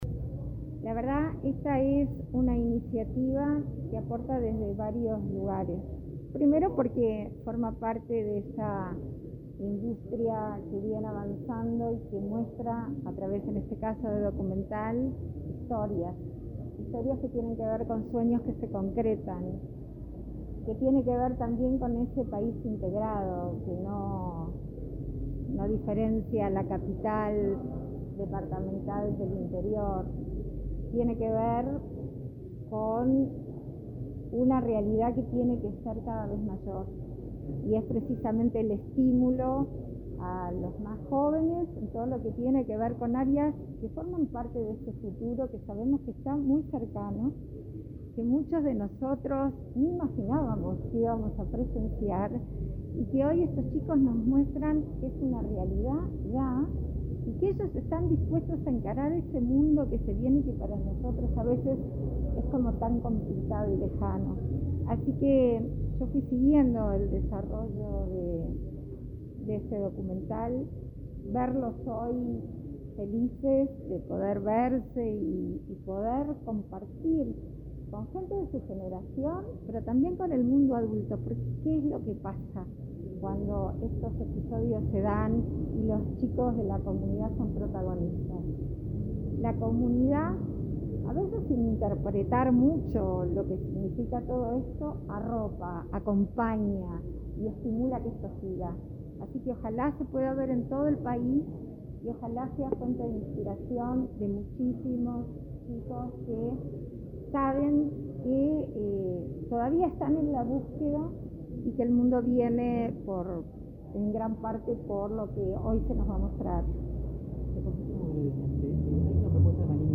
Declaraciones a la prensa de la vicepresidenta Beatriz Argimón
Este lunes 25, la vicepresidenta de la República, Beatriz Argimón, participó de la presentación de la película “Soñar robots”, del Plan Ceibal y la